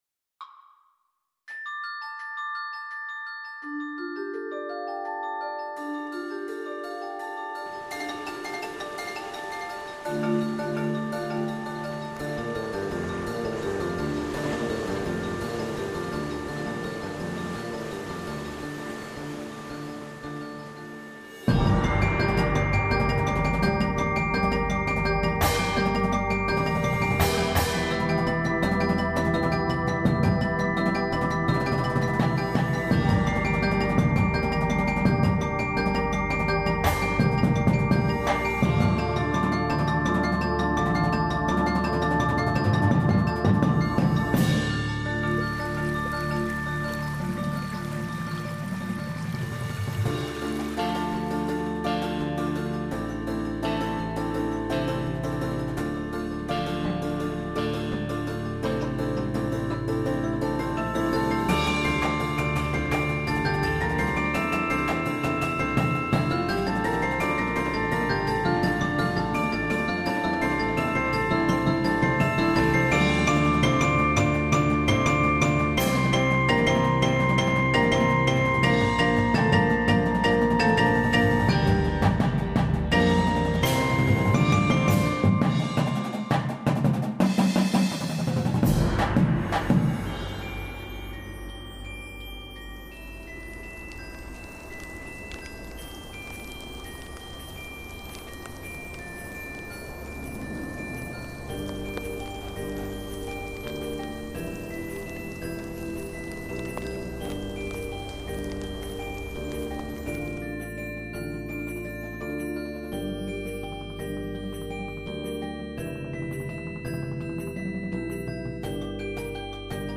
Snares
Tenors (Quads)
Bass Drums (5)
Glockenspiel
Xylophone
Marimba
Vibraphone
Electric Guitar
Bass Guitar
Synth
Auxiliary Percussion 1, 2